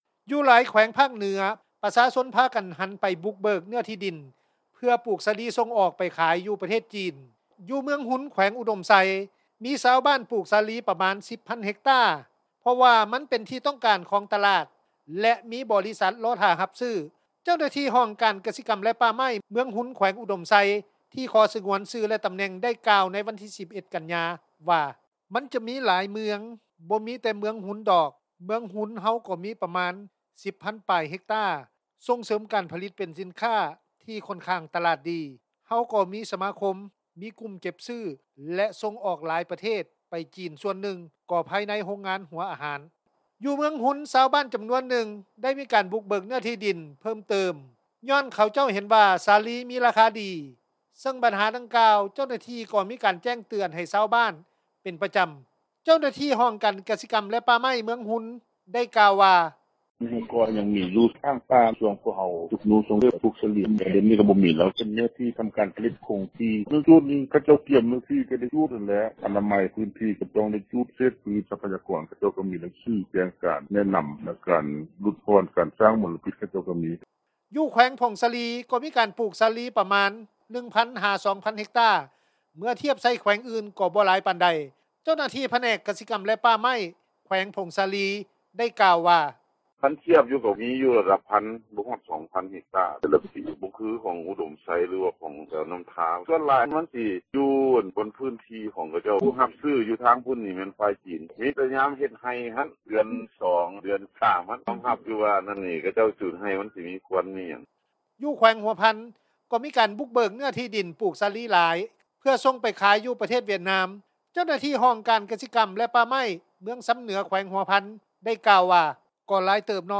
ຊາວບ້ານ ໃນແຂວງອຸດົມໄຊ ໄດ້ກ່າວວ່າ: